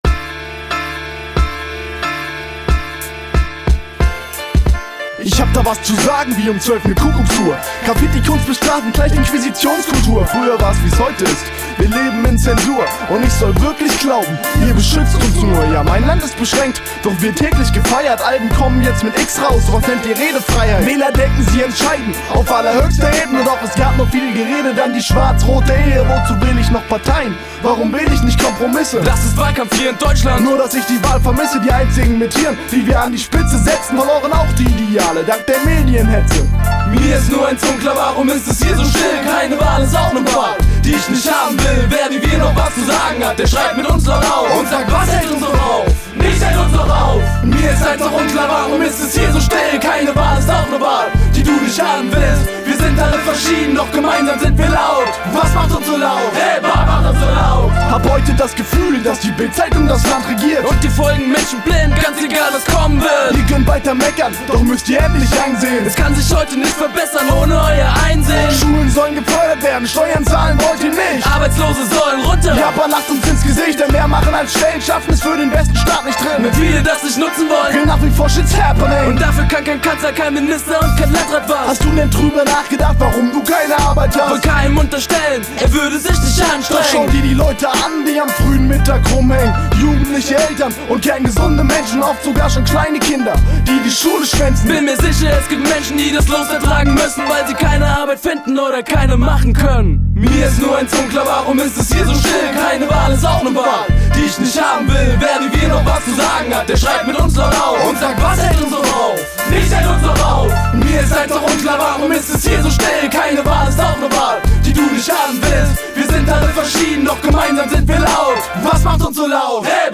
Die Rapcrew für Selters